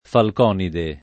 [ falk 0 nide ]